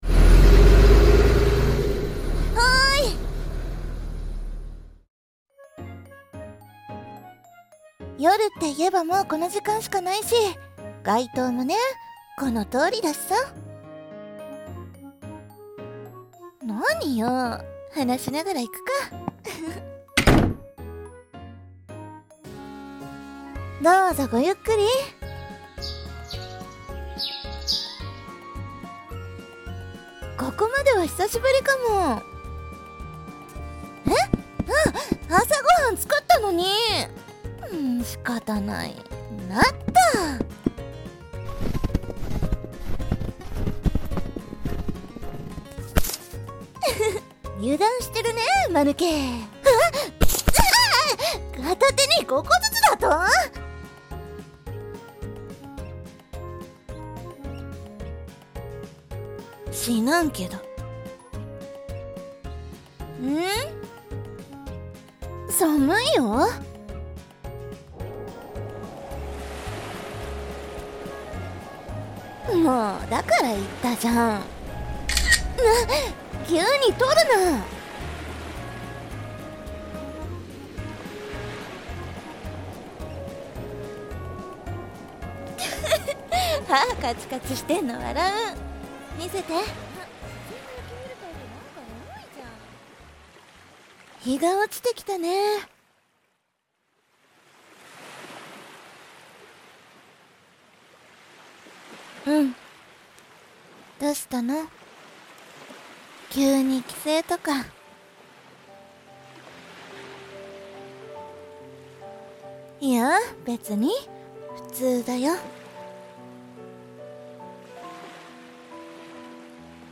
幾年重ねようと 【掛け合い 2人 声劇】